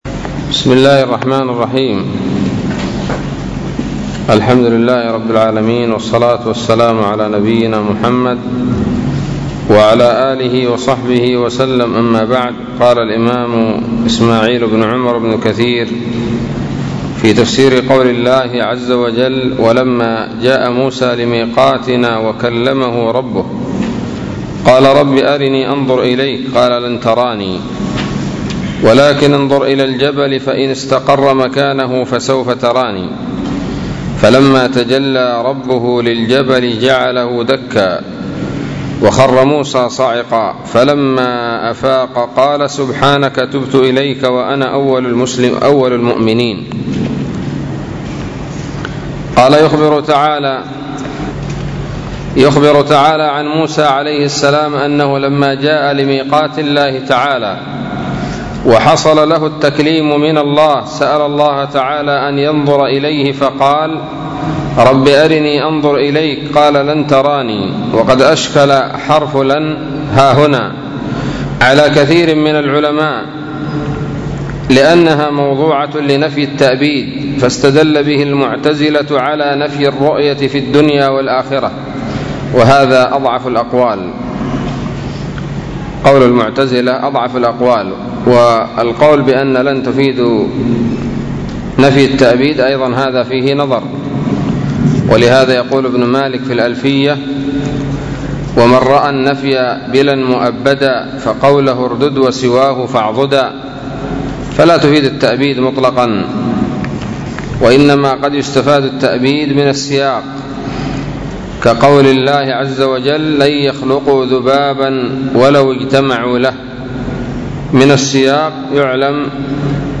الدرس الرابع والأربعون من سورة الأعراف من تفسير ابن كثير رحمه الله تعالى